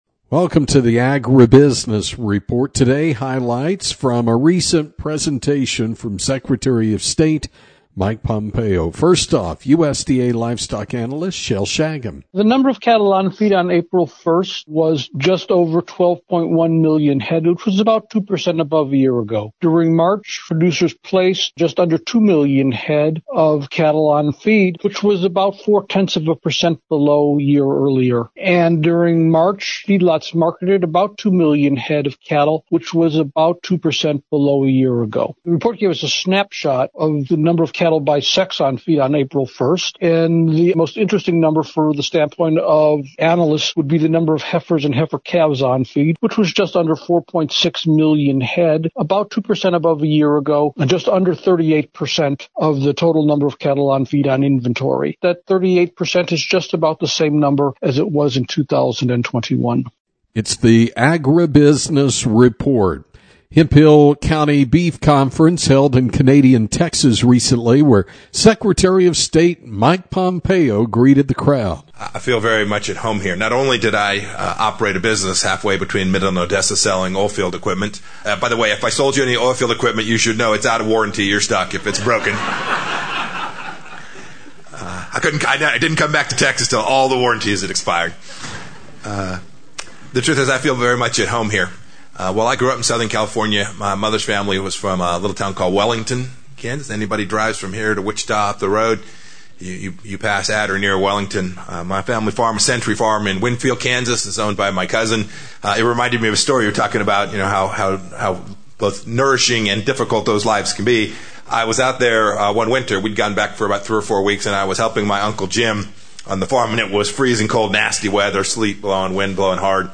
AGRIBUSINESS REPORT PODCAST – Guest: Mike Pompeo is the former Secretary of State and headlined the Hemphill County Beef Conference in Canadian, TX talking about the importance of agriculture to the country, some of the challenges the nation has had and is having, and what the future looks like from his perspective